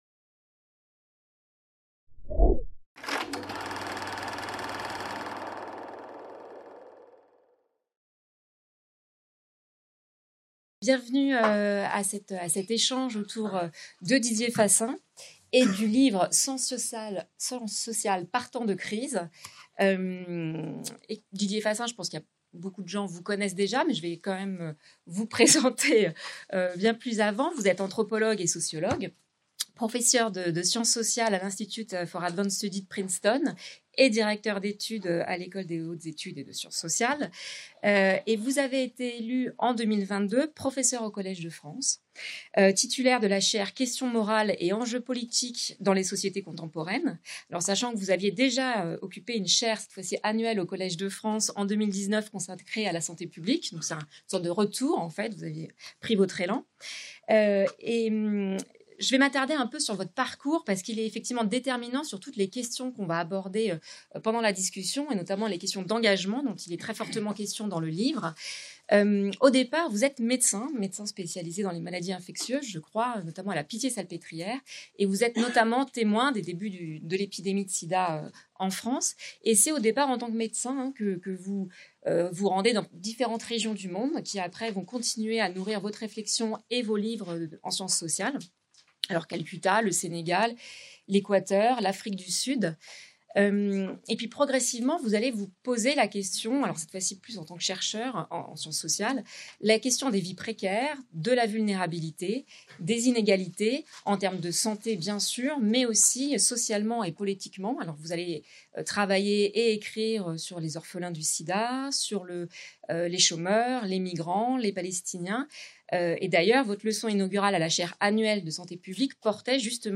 Soirée de présentation de l'ouvrage Sciences sociales par temps de crise | Canal U